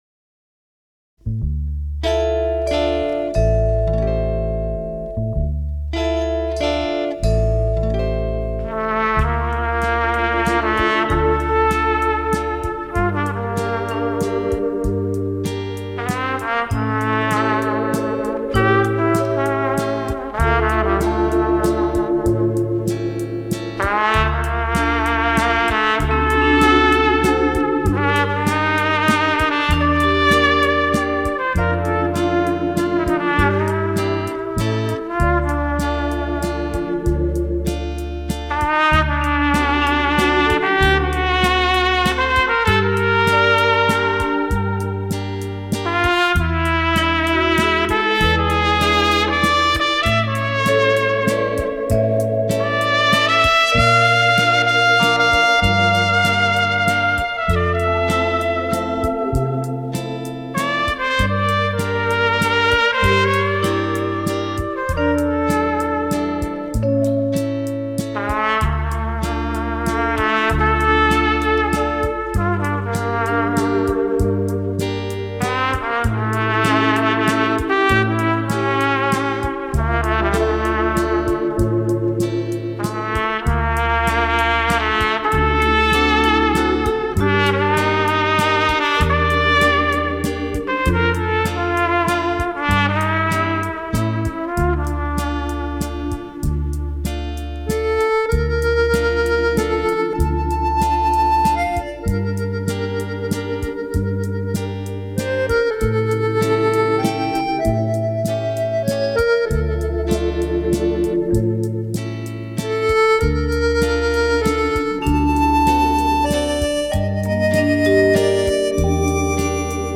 Какая красивая мелодия!